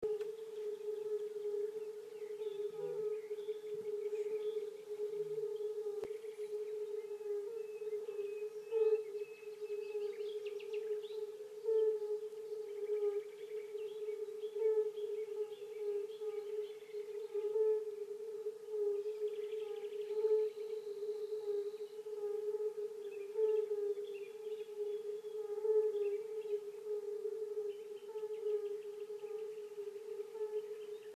Hier bekam das Summen Konturen und wurde zu einem vielstimmigen Chor von Irgendwassen.
Später auf dem Rückweg, es war mittlerweile ganz dunkel, war das Geräusch immer noch da und einem gnädigen Schicksal ist es vermutlich zu verdanken, dass wir diesmal über die 1 Meter mal 1 Meter große Hinweistafel stolperten, die das Gewässer als Unkenteich, als Reproduktionszentrum für Rotbauchunken gar auswies.
Rotbauchunken
(Ganz am Anfang hört man zweimal, bei Sekunde 2 und 5, das dumpfe Hupen eines Rohrdommel-Männchens, leider nur leise und kurz, aber diese tiefen Töne, die man bisweile eher spürt als hört, waren den ganzen Abend über hörbar, mal lauter, mal leiser, aus verschiedenen Richtungen.
Im Hintergrund zentnerweise Nachtigallen und etwa in der Mitte ein Rohrschwirl mit seinem monotonen Schnarren.)